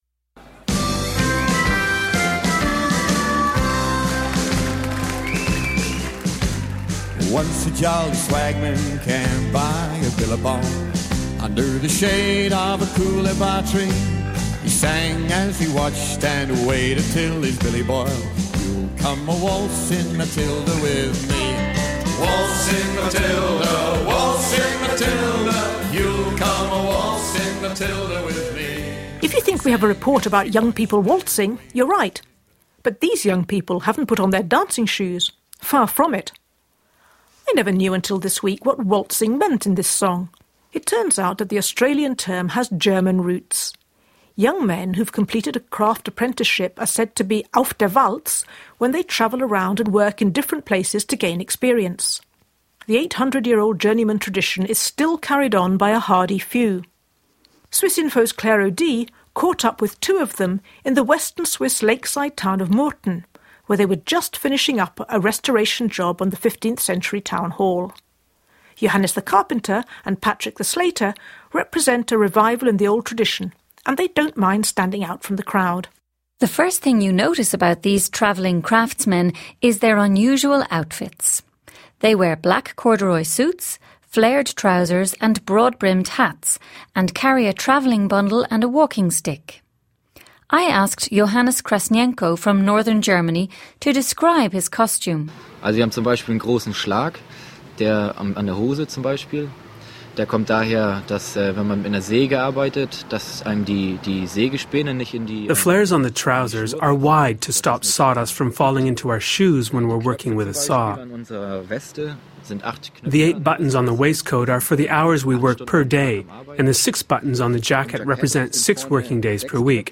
Two journeymen describe an 800 year old tradition.